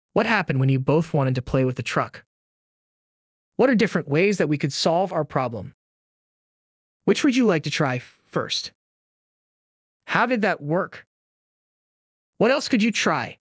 Select the audio clip to hear an example of a teacher asking questions that encourage reflection and problem solving.